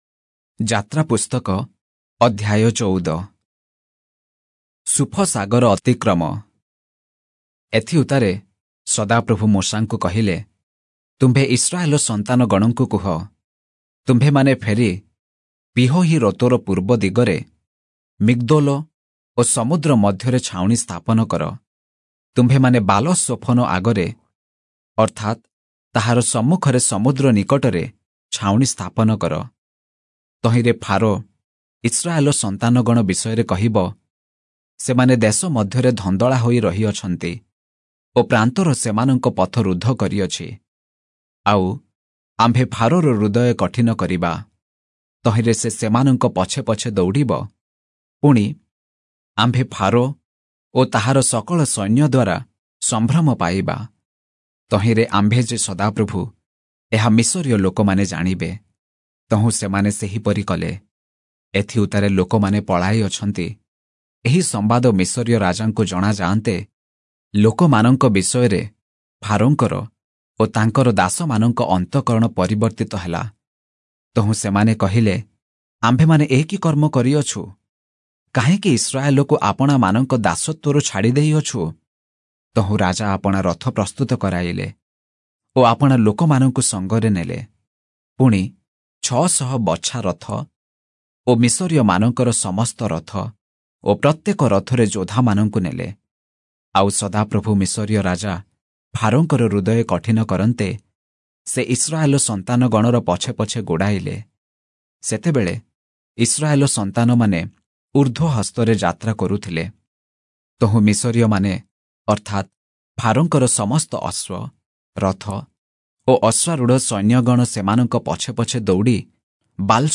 Oriya Audio Bible - Exodus 1 in Irvor bible version